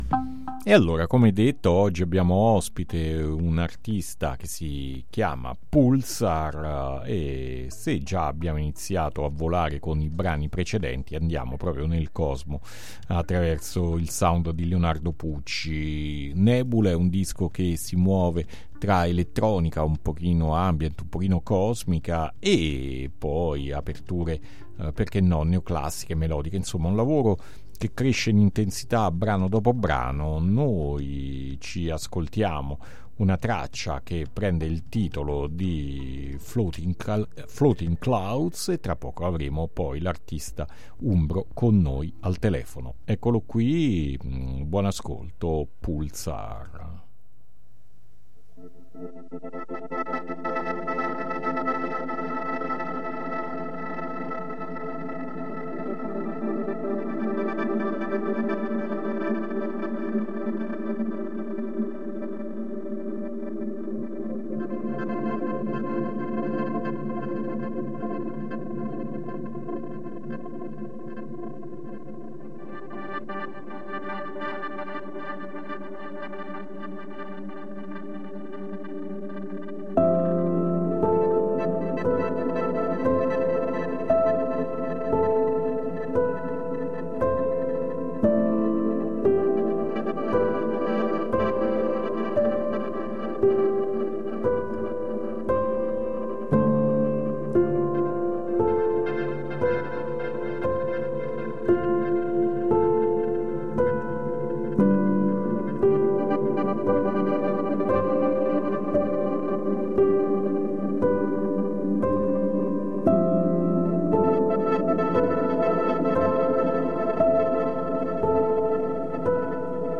INTERVISTA PULSAR AD ALTERNITALIA 8-7-2022